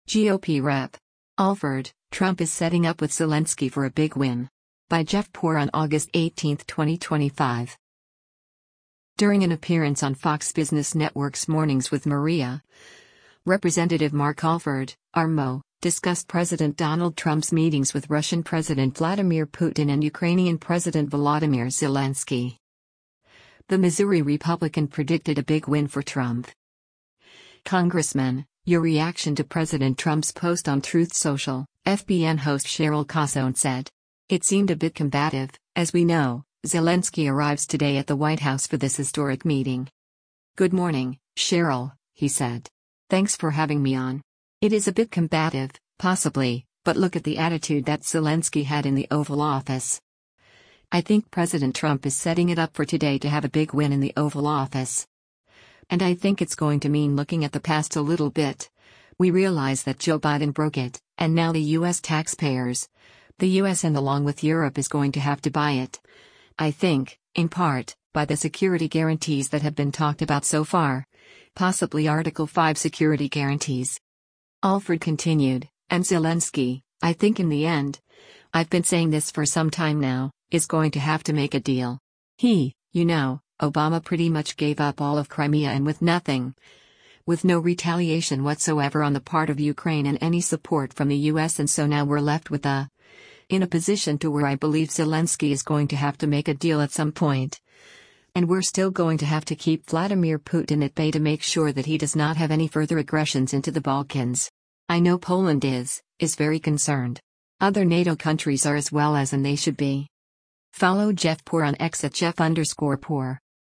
During an appearance on Fox Business Network’s “Mornings with Maria,” Rep. Mark Alford (R-MO) discussed President Donald Trump’s meetings with Russian President Vladimir Putin and Ukrainian President Volodymyr Zelensky.